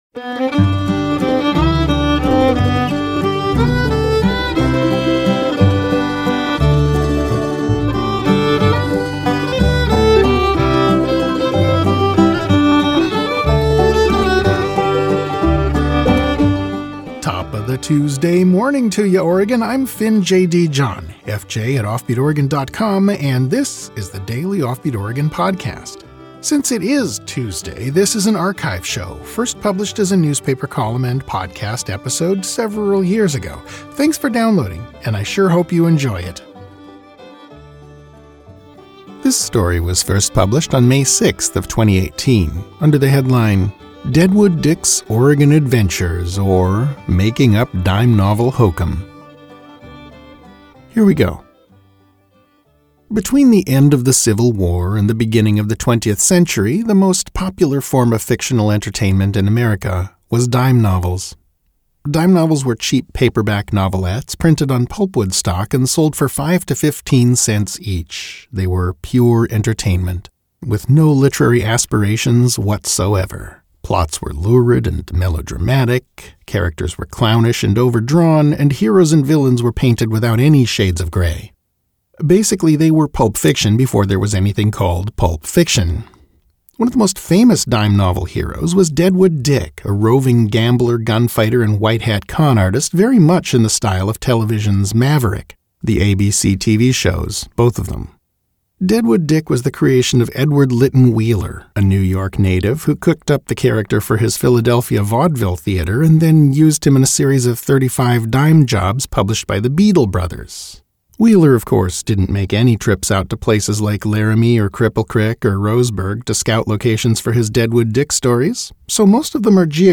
Audio verison of this article